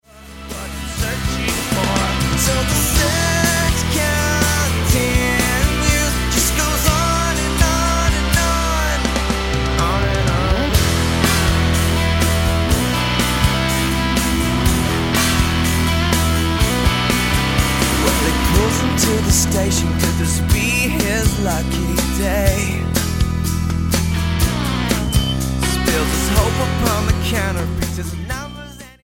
8203 Style: Rock Approach